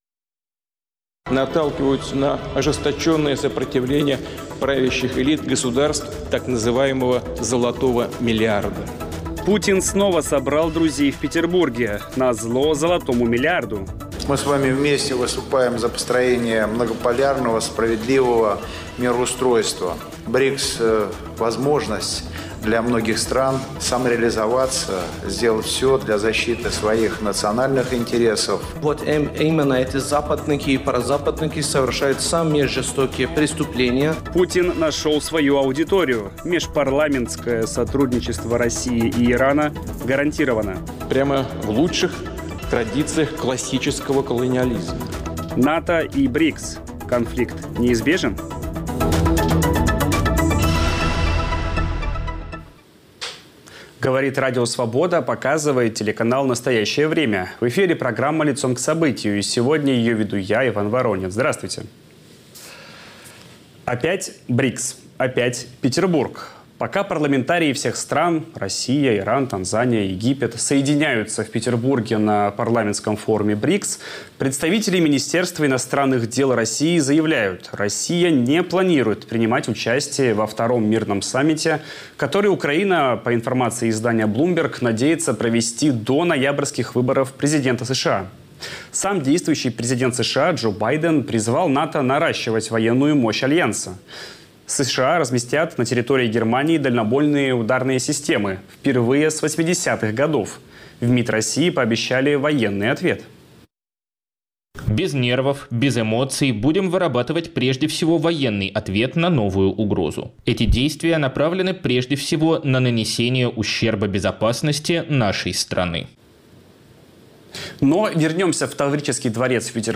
Об этом говорим с политологом